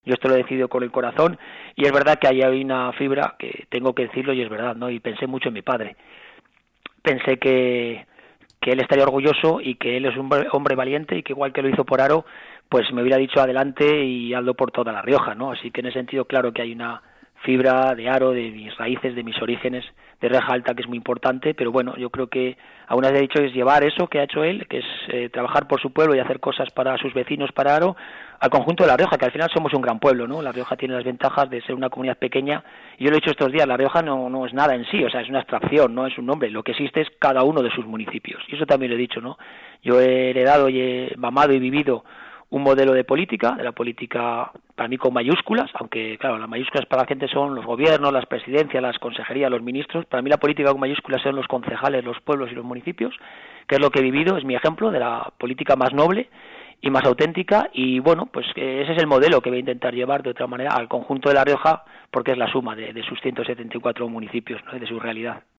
El candidato del PP a los próximos comicios autonómicos, el jarrero Gonzalo Capellán, ha asegurado en RADIO HARO que pensó mucho en su padre, el que fuera alcalde de la ciudad jarrera, Patricio Capellán, a la hora de dar el paso y asumir el liderazgo del PP en La Rioja como cabeza de lista a la Presidencia de la región.